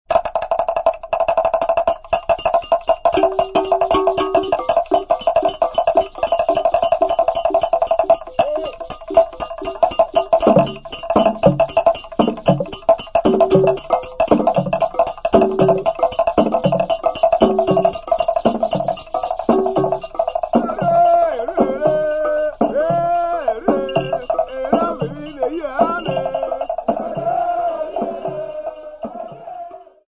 Mayogo men and women
Folk music--Africa
Field recordings
sound recording-musical
Indigenous folk song for the 'Ebi dance', with singing accompanied by 1 very small pod-shaped drum, 2 large pod-shaped drums, 1 double metal bell, 2 axe blades, 1 small wristbell and 1 rattle.